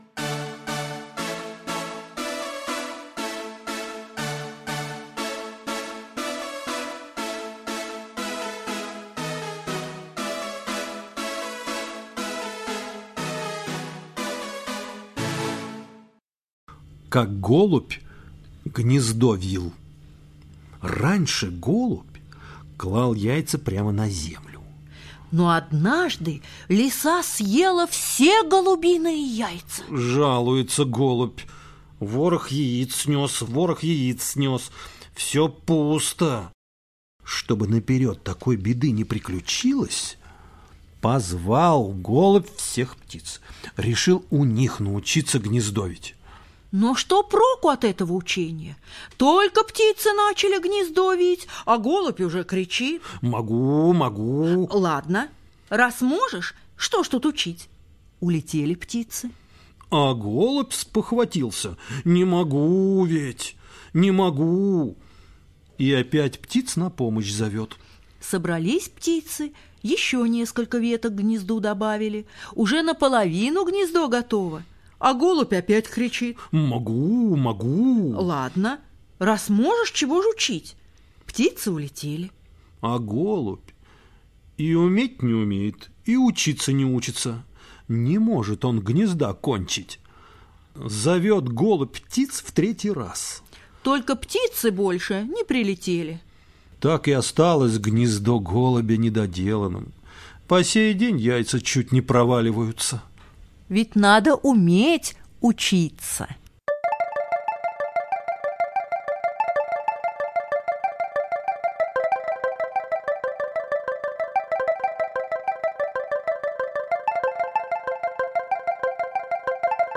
Как голубь гнездо вил - латышская аудиосказка - слушать онлайн